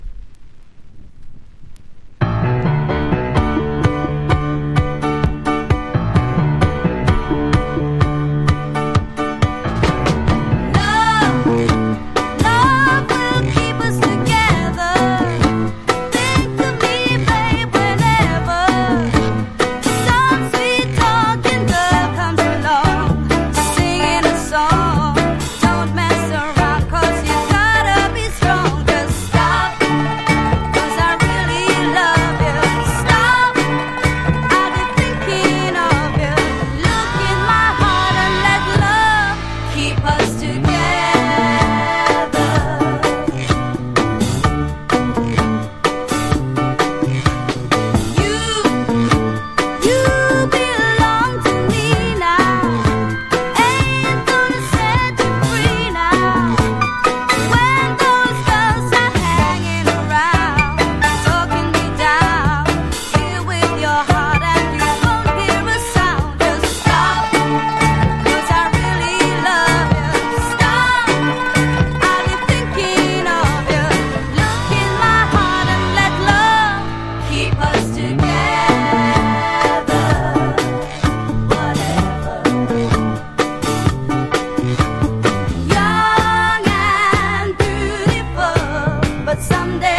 トリニダード・トバゴ出身、イギリスで活躍した男女兄弟デュオの'74年アルバム！